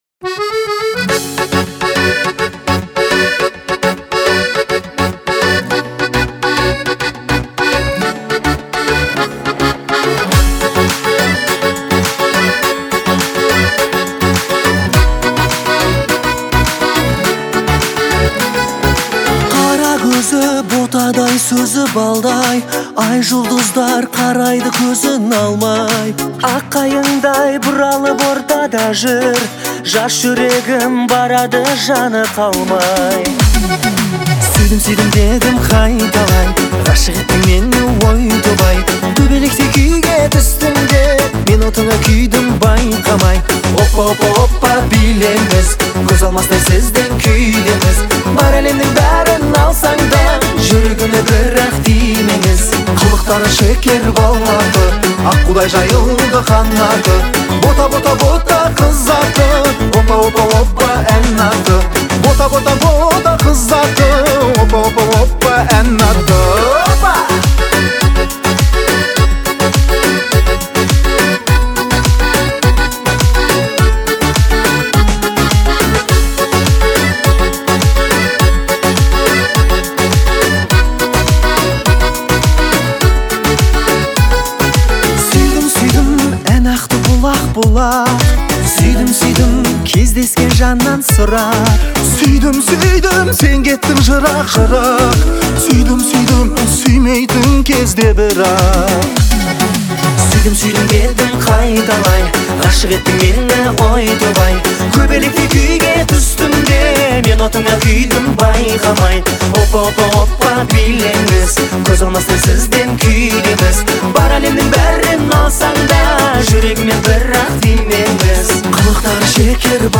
зажигательная песня